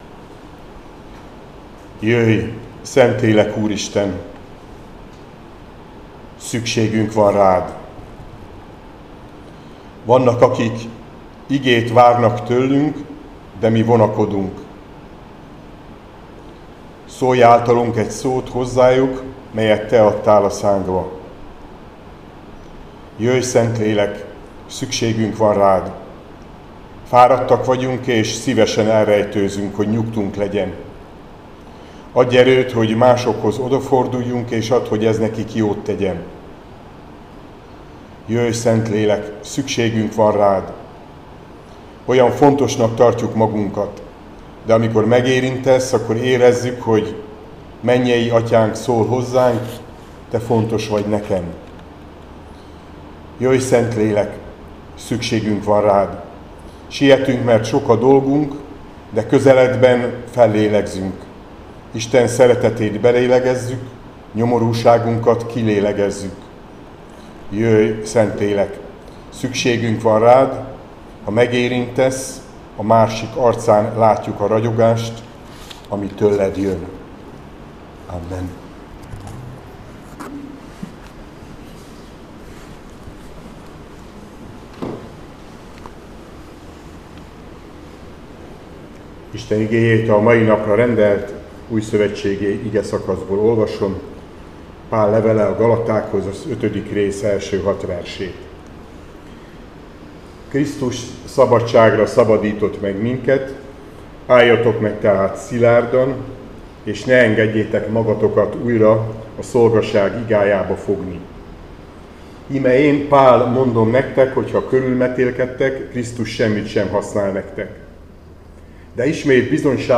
Áhítat, 2025. június 3.
Gal 5,1.6 Balog Zoltán püspök